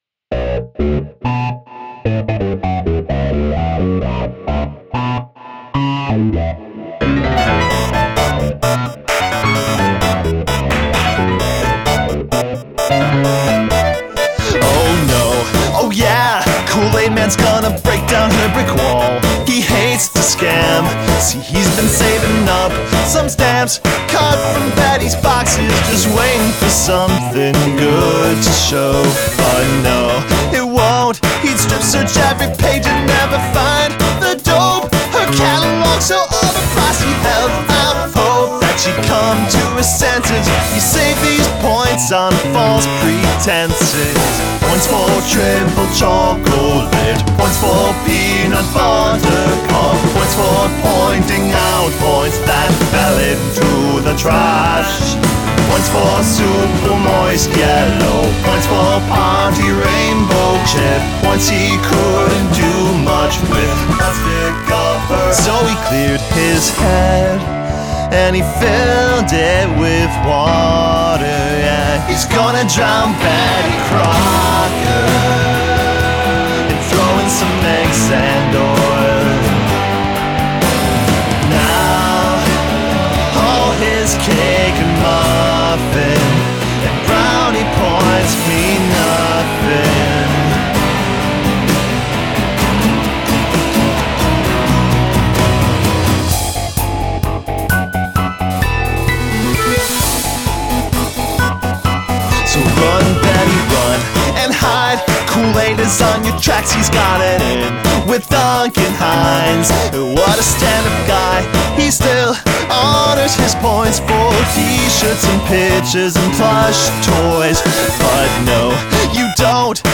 Time Signature Changes